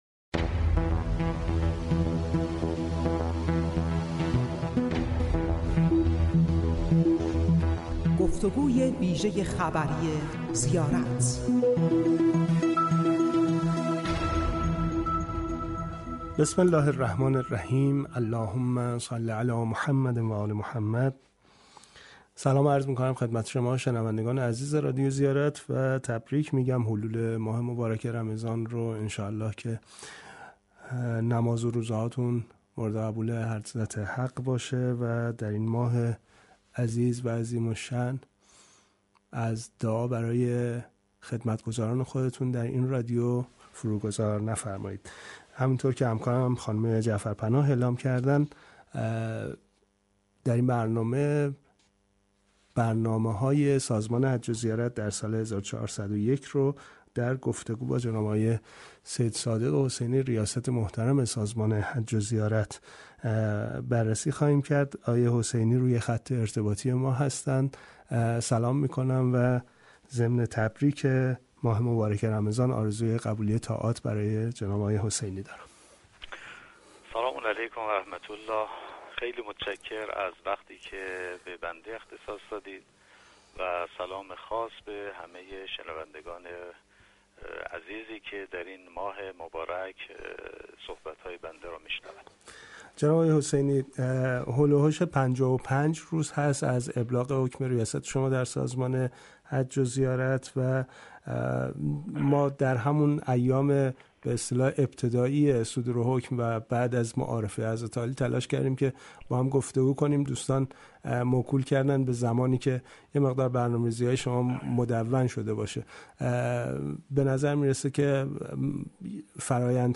به گزارش رادیو زیارت، سید صادق حسینی در گفتگوی ویژه خبری این رادیو با بیان اینکه وضع برگزاری حج 1401 هنوز نامعلوم است، افزود: با توجه به مکاتبات صورت گرفته منتظر تصمیم عربستان هستیم.